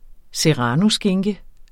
Udtale [ seˈʁɑno- ]